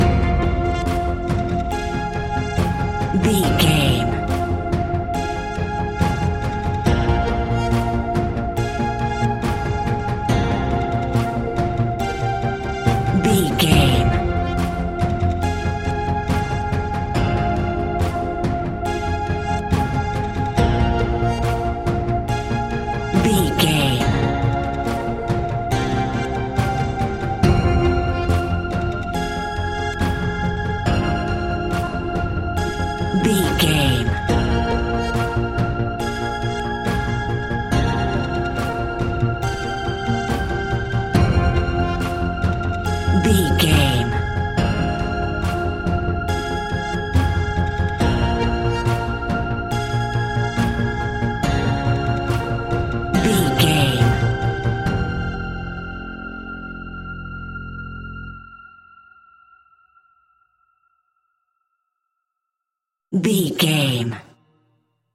In-crescendo
Thriller
Aeolian/Minor
ominous
dark
eerie
synthesizer
horror music
Horror Pads
Horror Synths